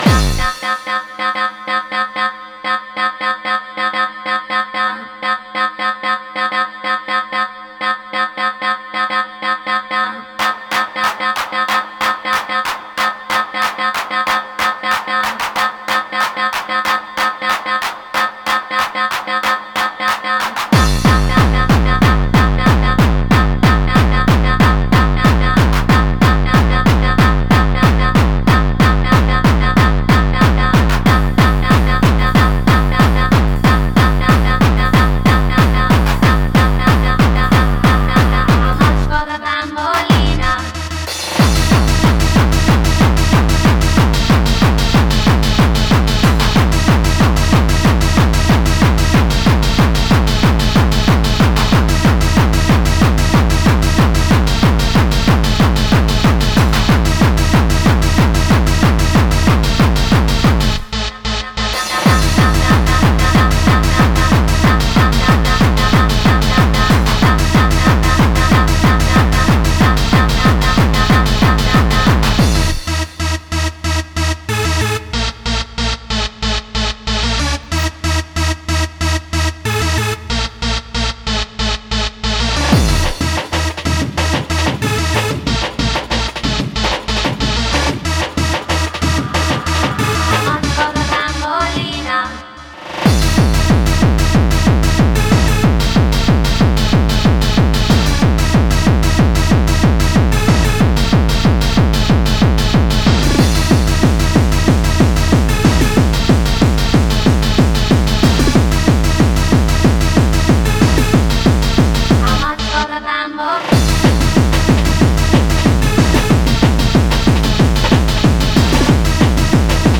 Genre: Hardcore.